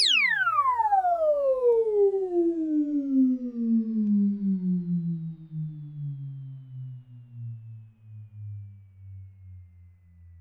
descending-noise.wav